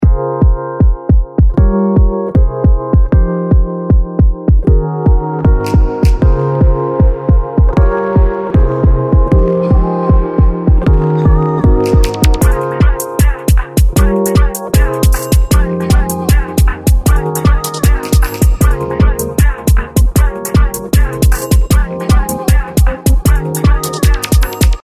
他、特徴的でいて使いまわしも効くリバーブ系の「Glued Verb」、サウンドの変化が面白い「Pan’s Labyrinth」もおすすめなので、Rhodesにかけてみました。